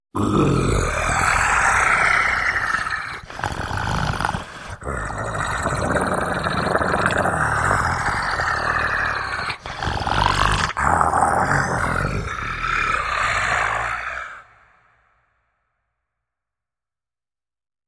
Хотя его существование не доказано, эти аудиозаписи помогут вам представить его голос: от глухого рычания до странных шорохов.
Звук храпящего снежного человека во сне